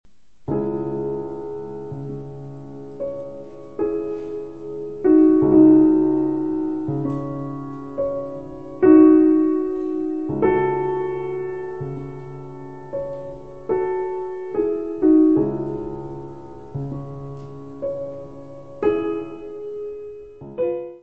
: stereo; 12 cm
piano
Music Category/Genre:  Classical Music
Andante sostenuto.